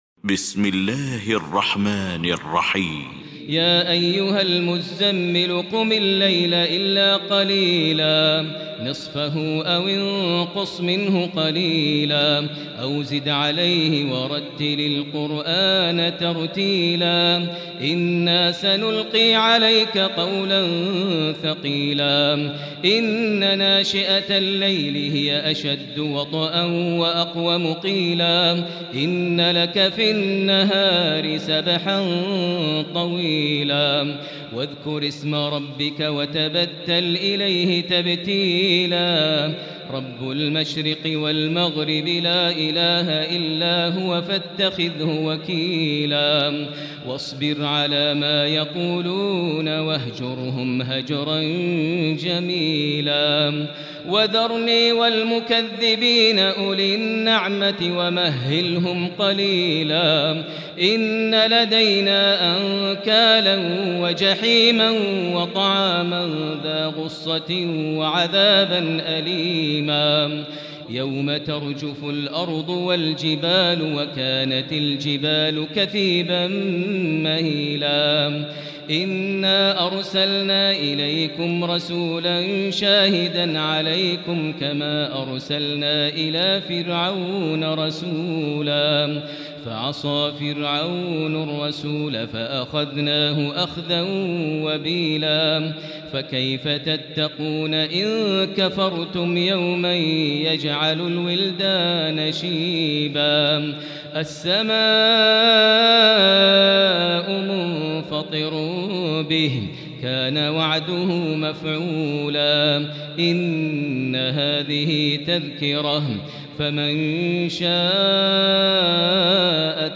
المكان: المسجد الحرام الشيخ: فضيلة الشيخ ماهر المعيقلي فضيلة الشيخ ماهر المعيقلي المزمل